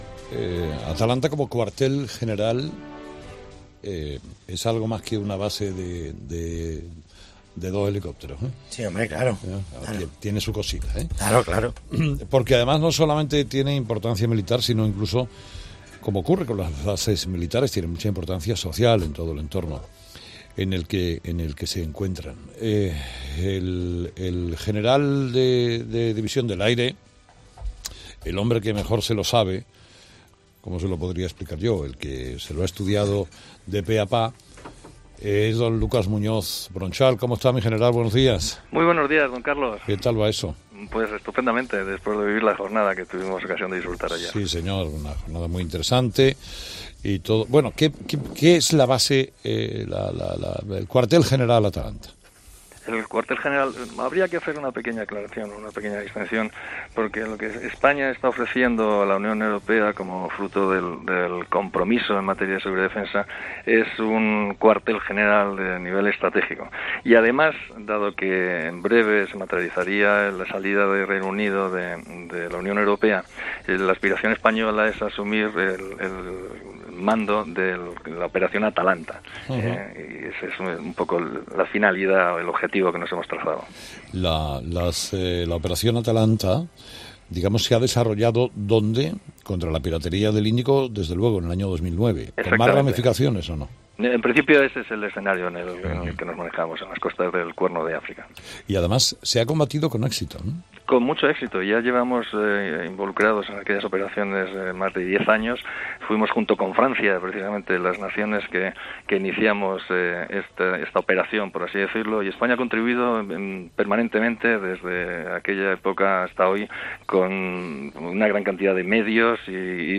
Entrevista a Lucas Muñoz Bronchal, general de División del Ejército del Aire